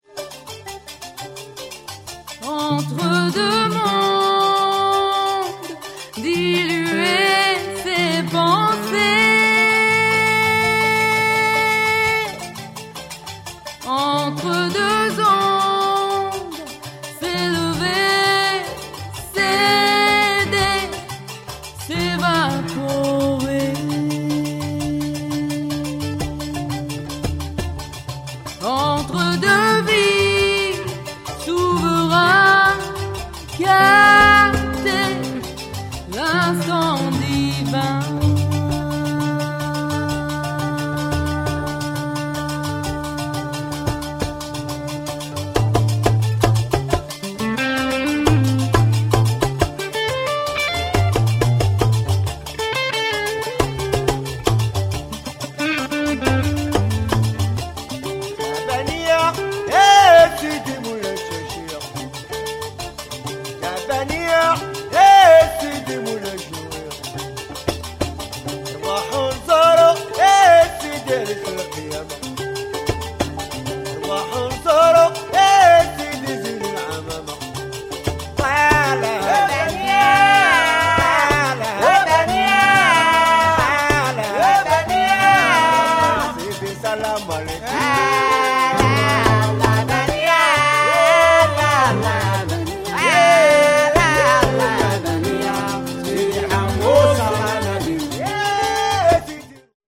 gnaoua gnawa"LA TRANSE SI PROCHE...